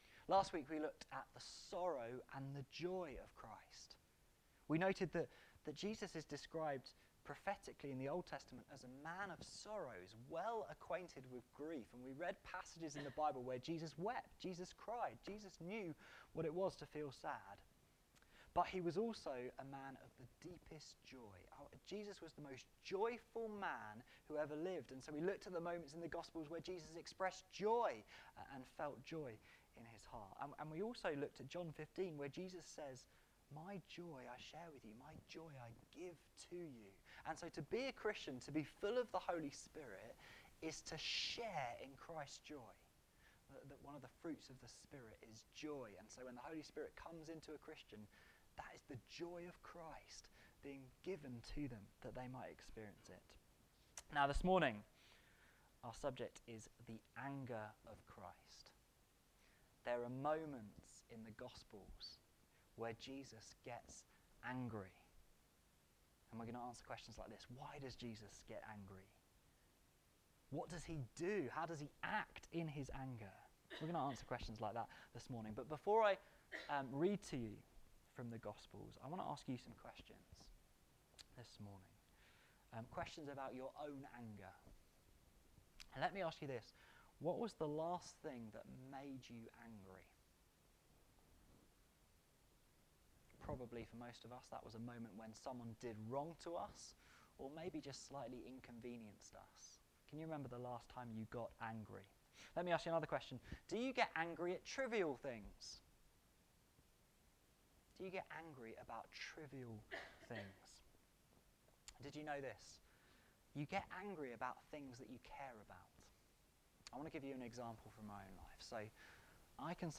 A thematic sermon on the moments in the Gospels where Jesus feels the emotion of compassion or pity.